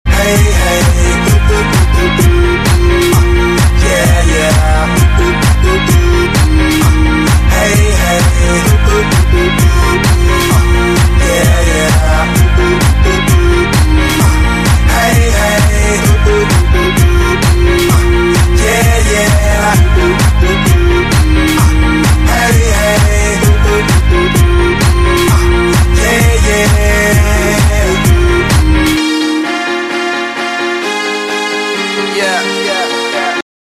• Качество: 128, Stereo
dance